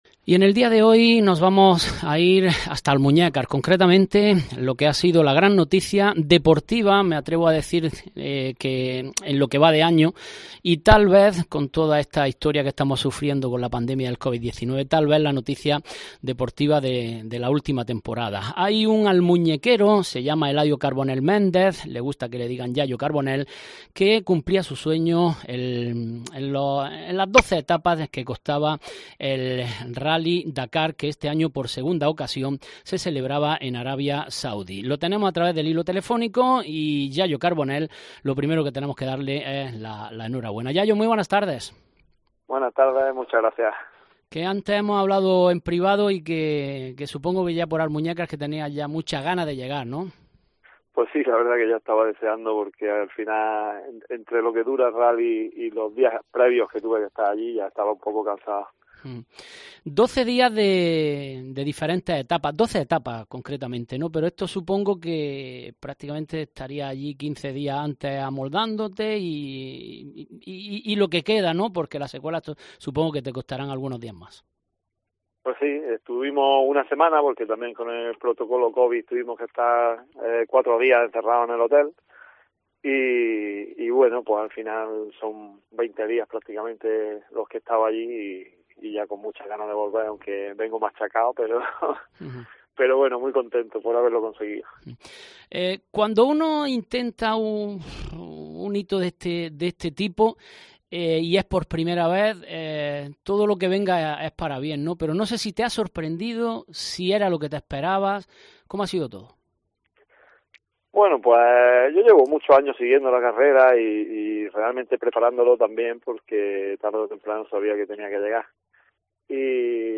En la entrevista que puede usted escuchar